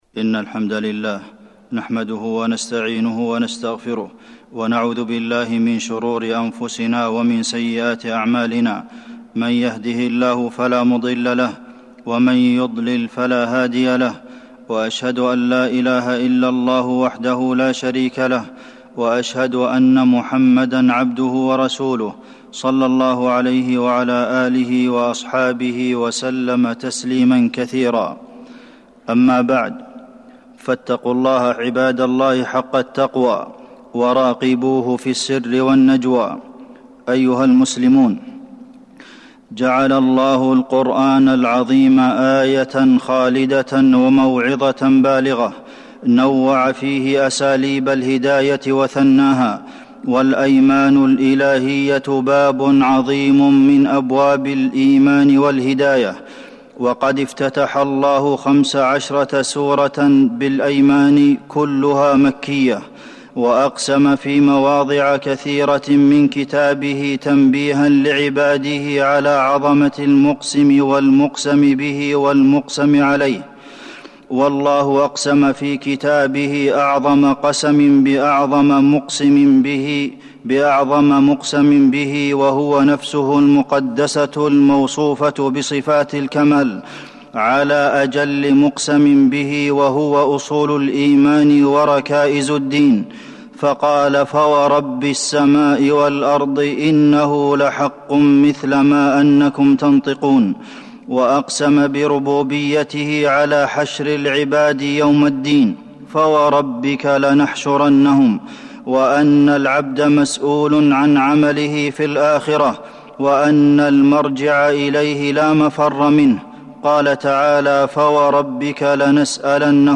تاريخ النشر ١٣ ربيع الأول ١٤٤٢ هـ المكان: المسجد النبوي الشيخ: فضيلة الشيخ د. عبدالمحسن بن محمد القاسم فضيلة الشيخ د. عبدالمحسن بن محمد القاسم الأيمان الإلهية